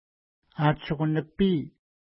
Pronunciation: a:tʃuku-nipi:
Pronunciation